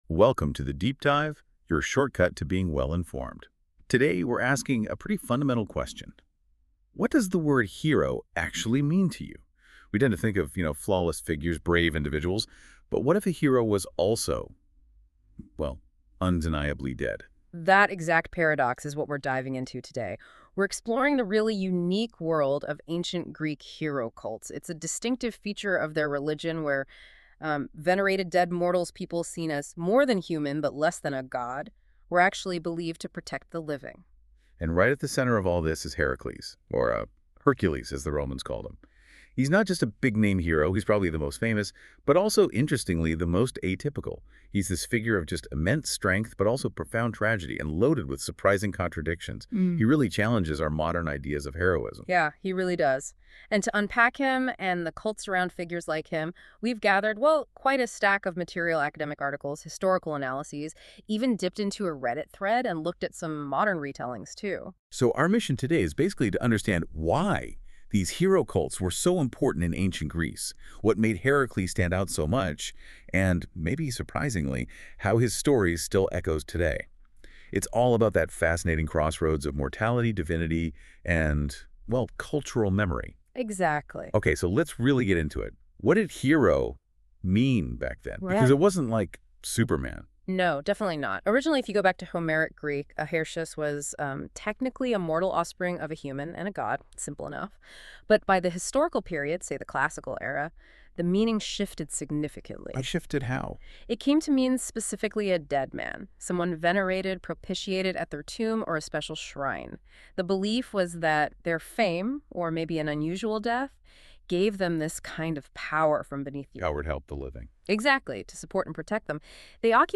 Podcast episode narrating the myth and legacy of Hercules. Discover the story, powers, and symbolism behind Hercules in Greek mythology.